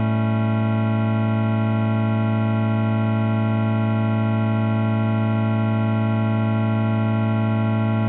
a7-chord.ogg